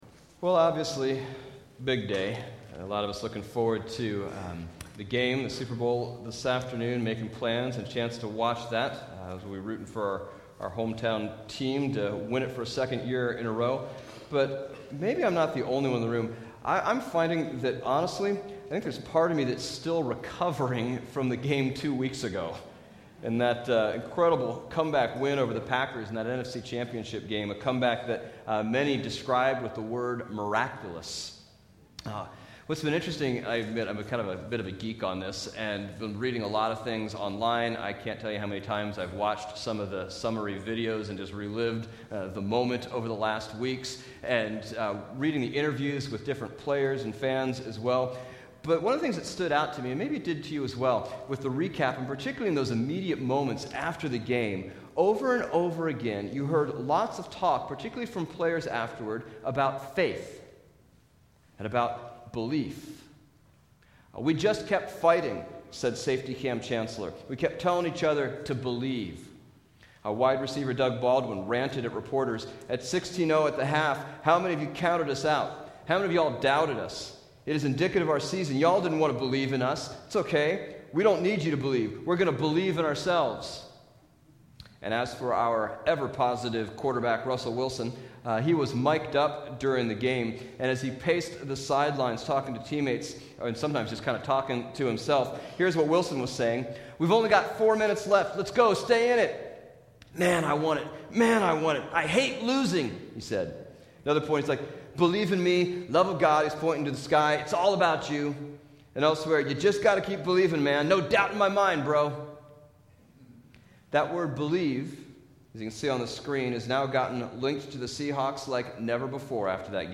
Bible Text: Luke 7:1-50 | Preacher